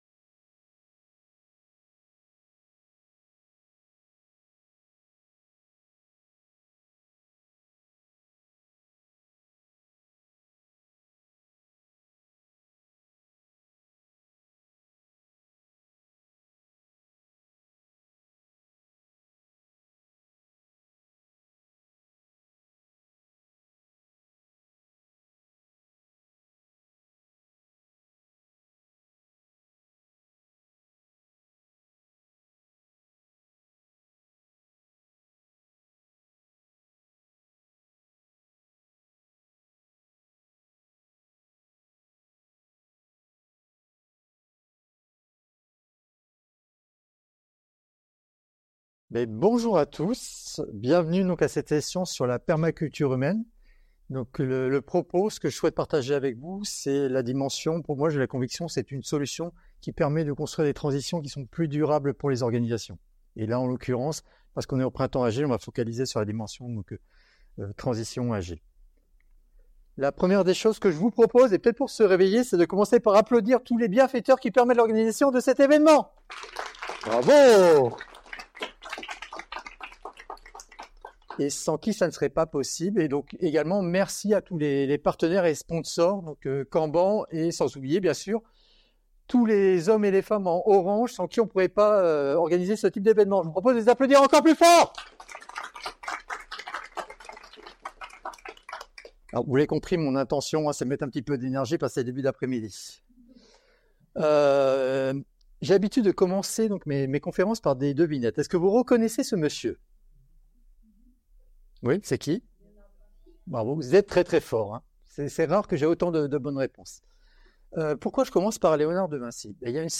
Le Printemps agile 2024 fait son festival